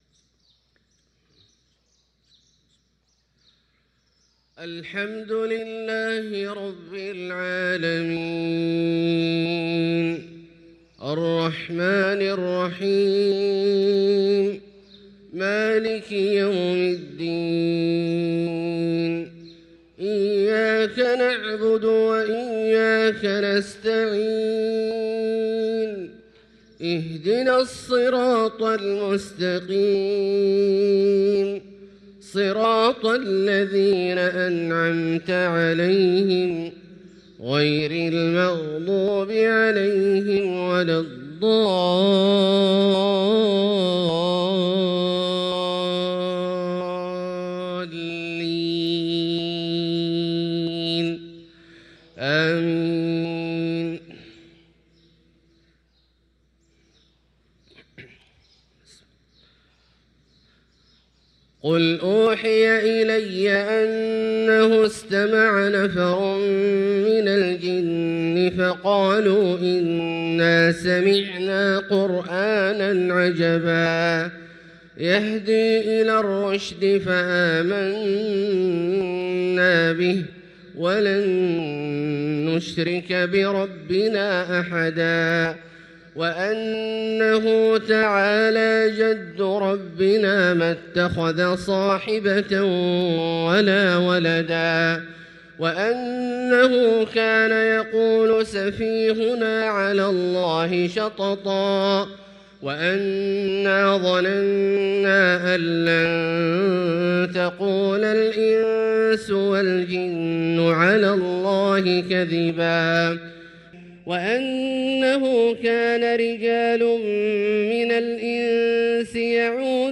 صلاة الفجر للقارئ عبدالله الجهني 18 جمادي الآخر 1445 هـ